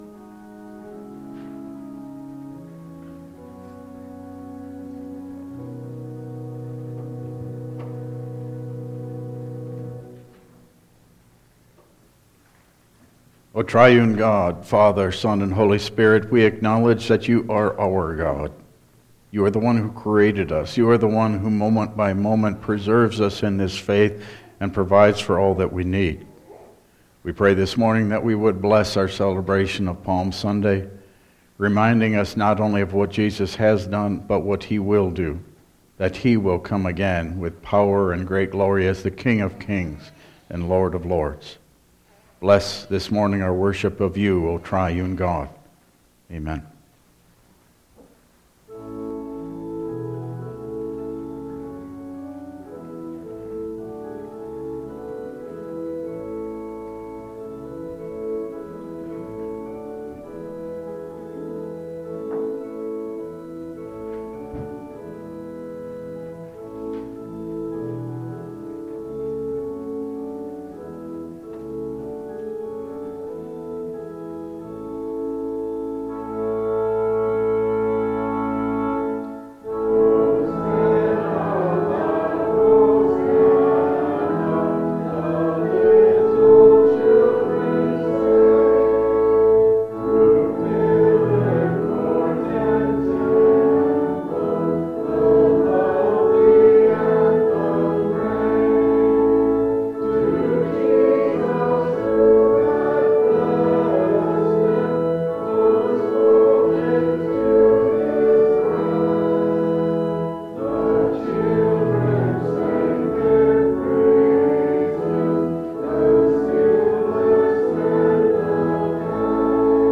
Passage: Luke 19:28-42 Service Type: Regular Service